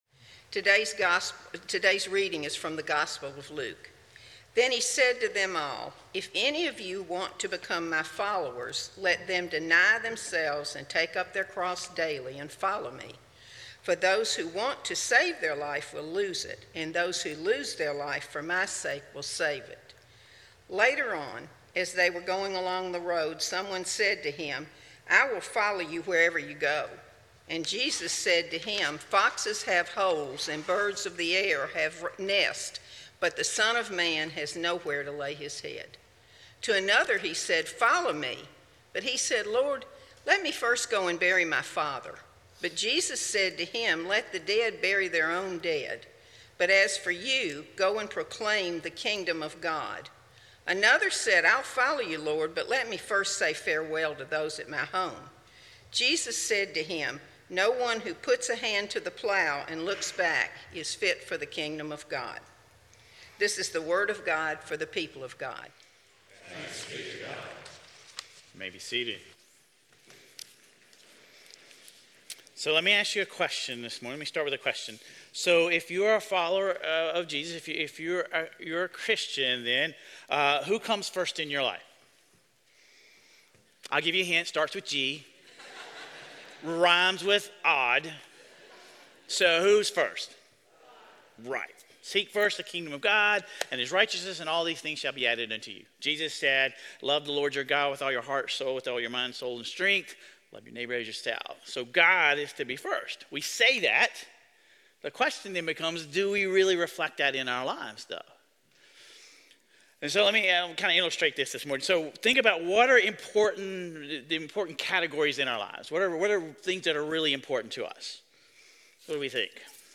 Sermon Reflections: If you were to honestly arrange the "chairs" of your life's priorities, where would God actually sit versus where you say God should sit?